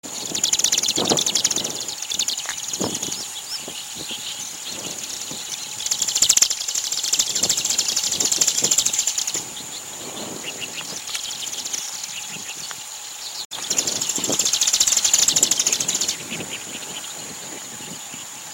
鳴 き 声：ジュクジュクジュクジュクと囀り飛翔をする。
鳴き声１